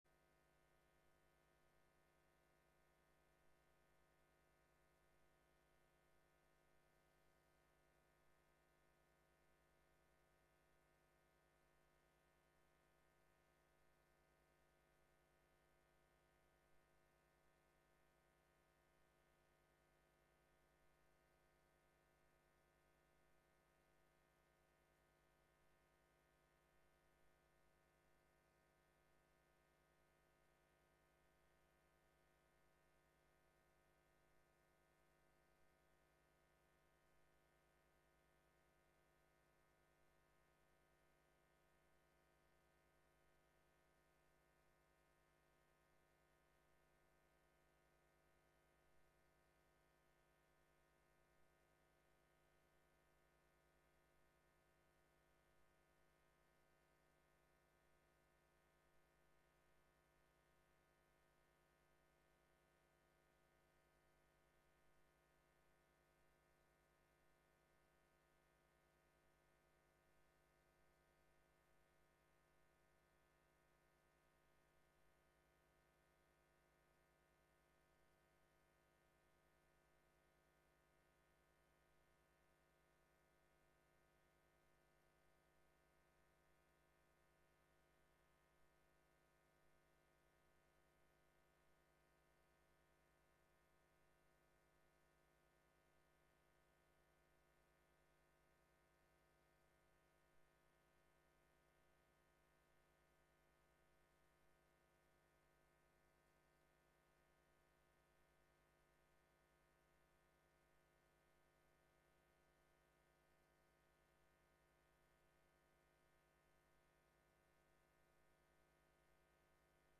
Genre:Irish Music, Talk, Community